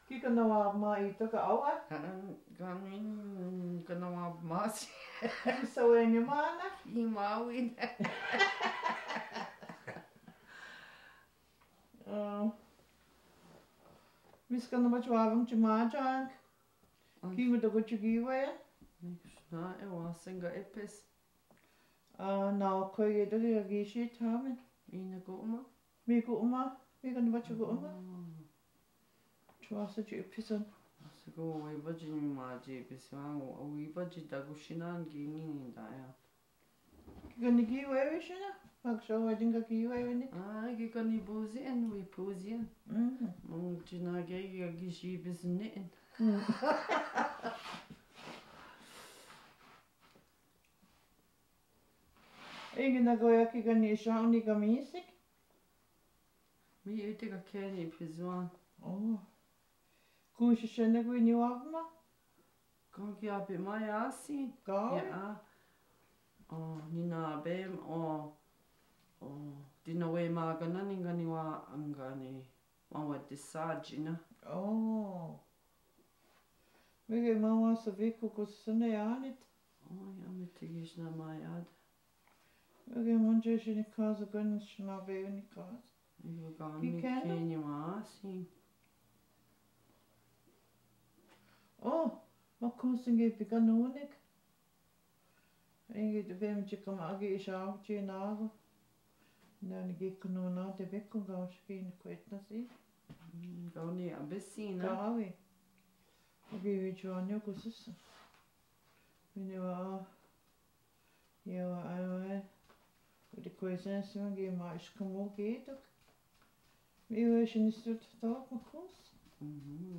Ojibwe Conversations Project OC_001 Conversation in Office